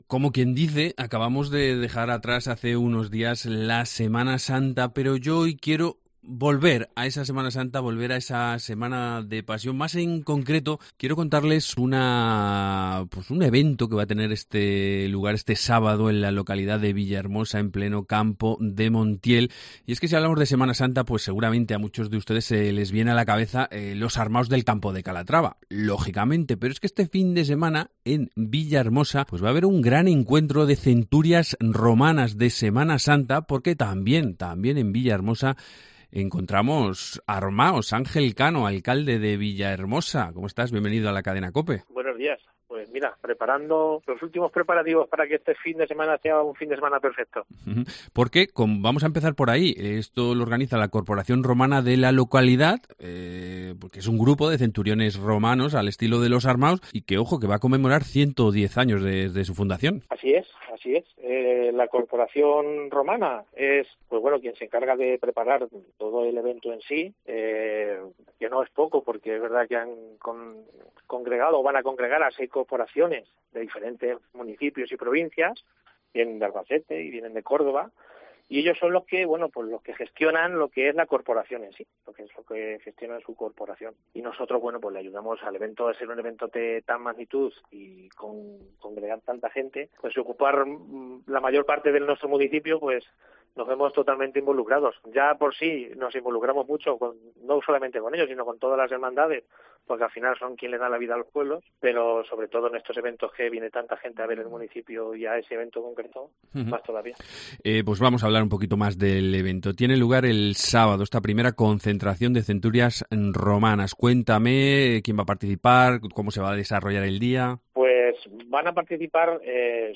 Entrevista con Ángel Cano, alcalde de Villahermosa